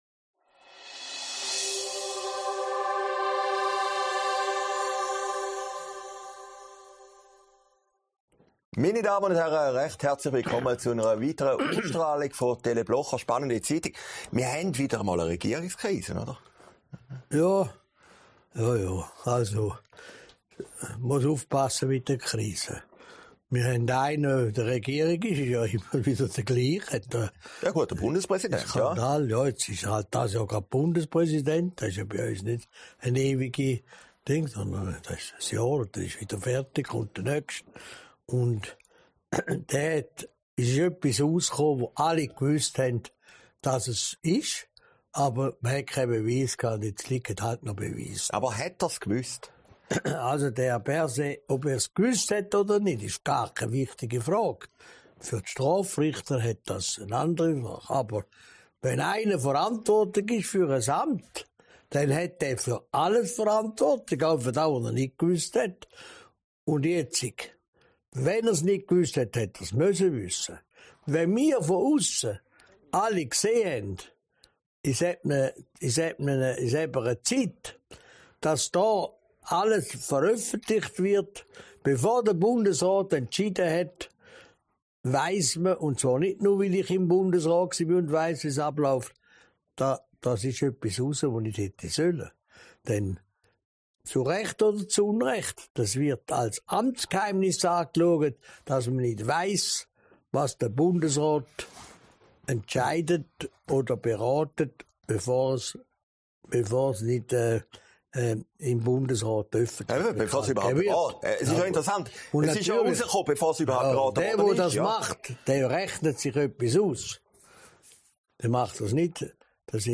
Sendung vom 27. Januar 2023, aufgezeichnet in Herrliberg